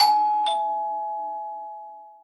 sounds_doorbell_01.ogg